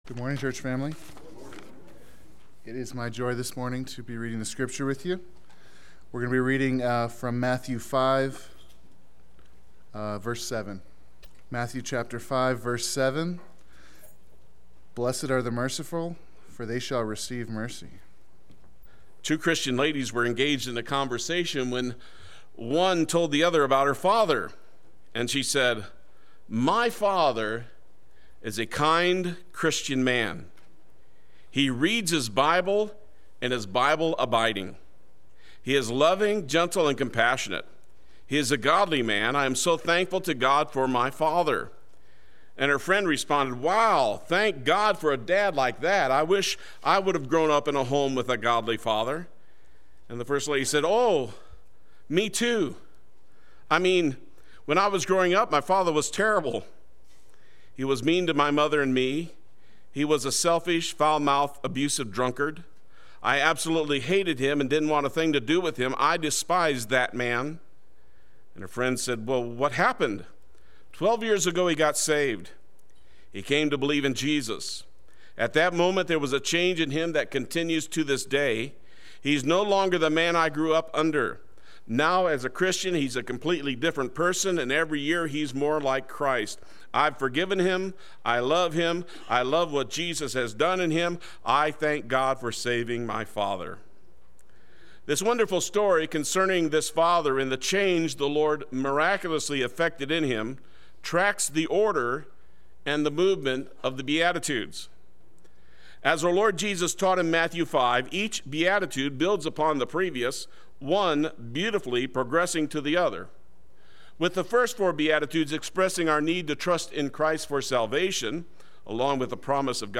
Three Blesseds Sunday Worship